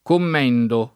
commendo [ komm $ ndo ]